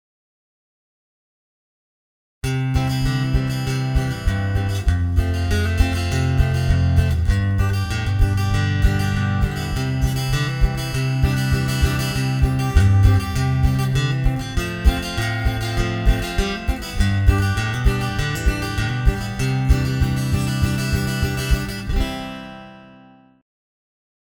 Country style pag 2.
Vediamo a questo punto una base composta da due chorus completi, che raccoglie tutto quello visto fino ad ora.
sola chitarra senza la base di batteria.